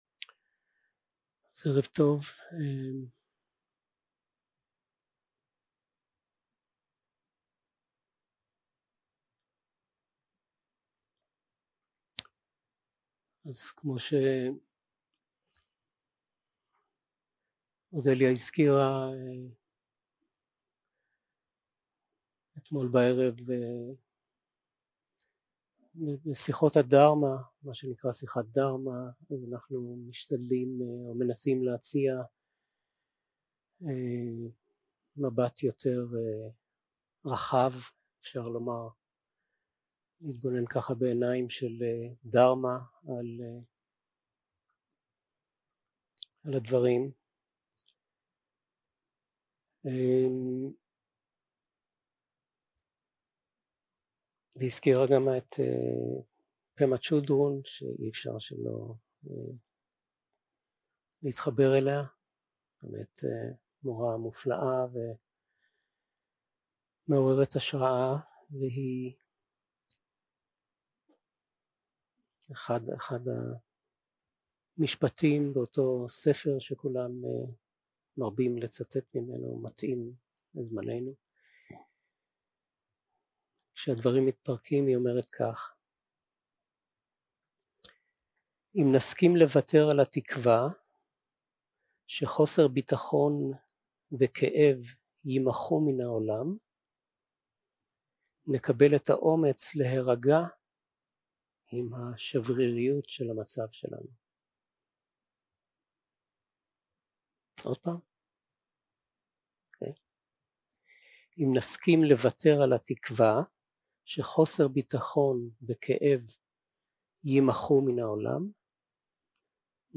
Dharma Talks שפת ההקלטה